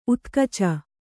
♪ utkaca